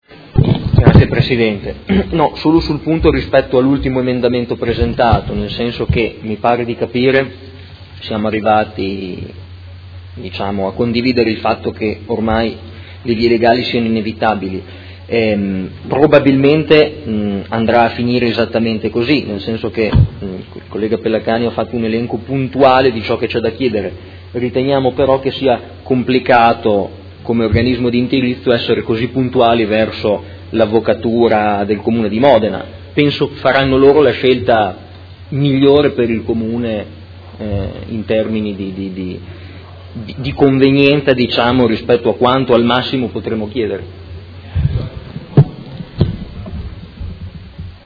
Seduta del 26/03/2018 Dibattito. Ordini del giorno Rotatoria di via Emilia Est. Chiede sospensione di alcuni minuti.